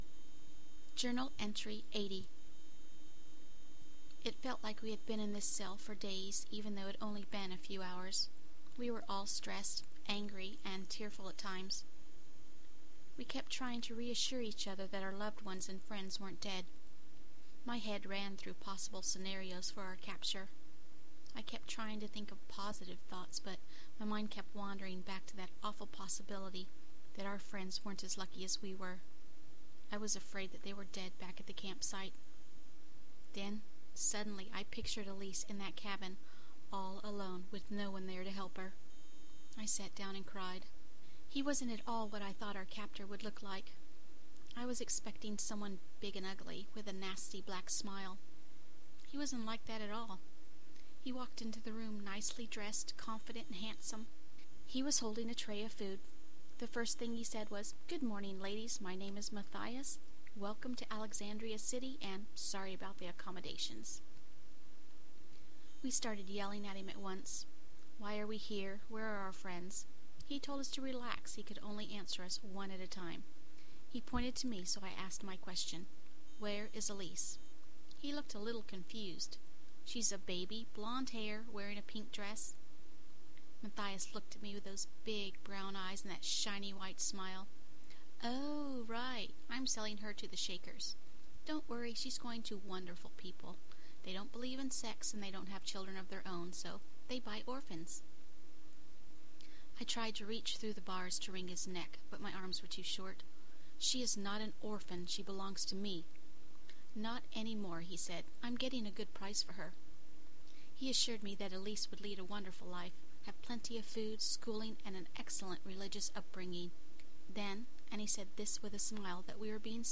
Genre: fiction, audio book.